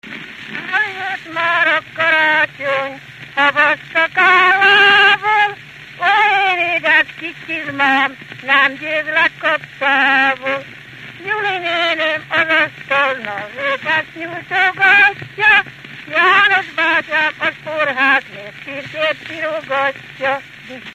Dunántúl - Veszprém vm. - Dudar
ének
Gyűjtő: Veress Sándor
Stílus: 6. Duda-kanász mulattató stílus